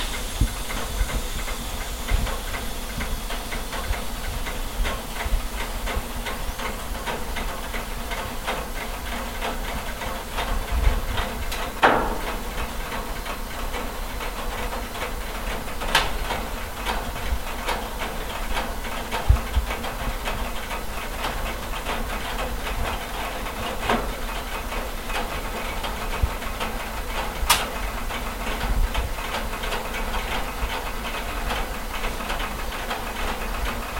缓慢开裂的冰
描述：慢慢地踩到冰上直到它破裂。
Tag: 裂缝 开裂 台阶 冷冻